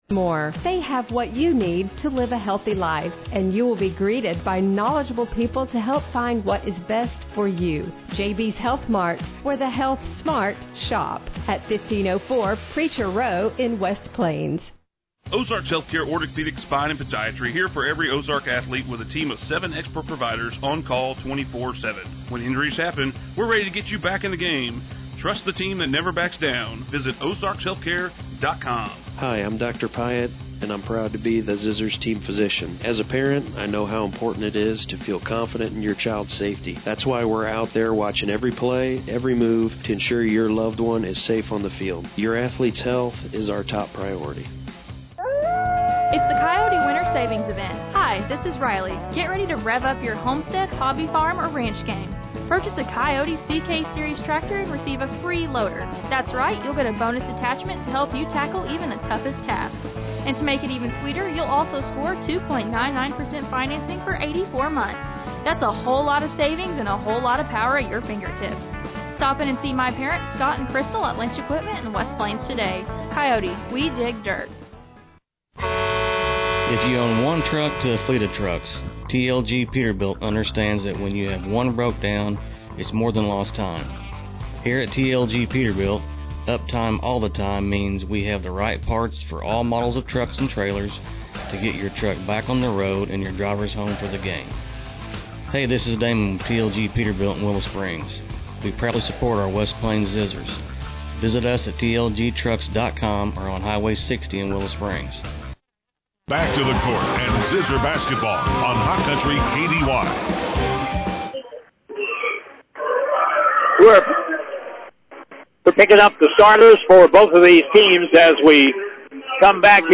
Game Audio Below: